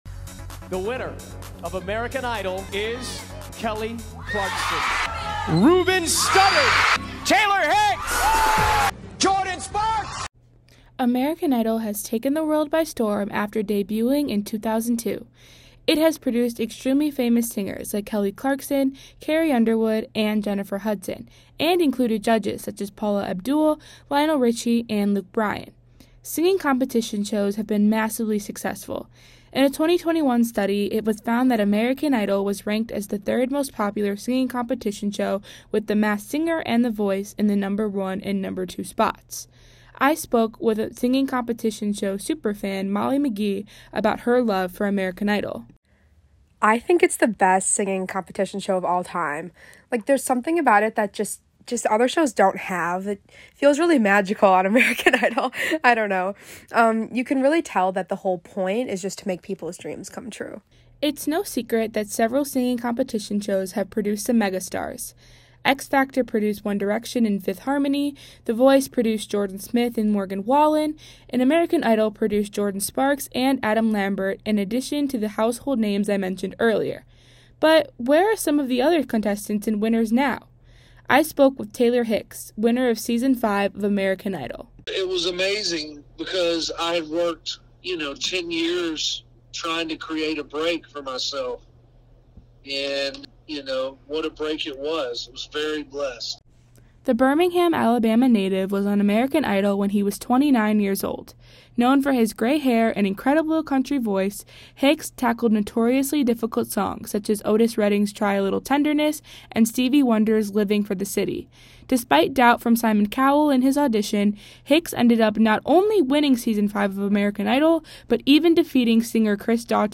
This story originally aired as part of our A.I. Special Broadcast.